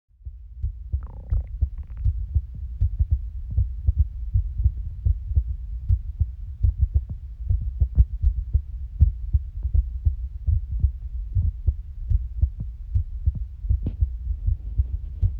heartbeat, plus bonus tummy sounds!